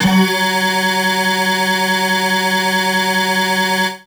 55bg-syn13-f#3.wav